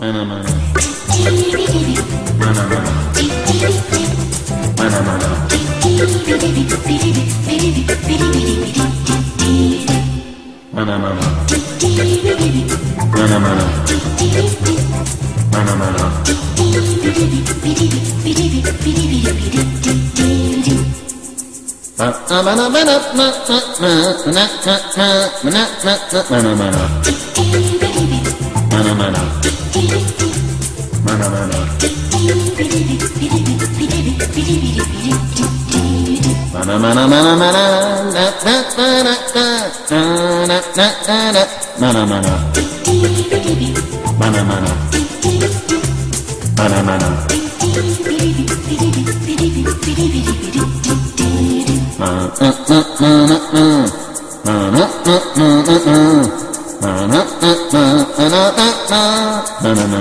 EASY LISTENING / OST / FRENCH GROOVE
ヒップな女性スキャットにハモンドとストリングス、打ったドラムが激グルーヴィーな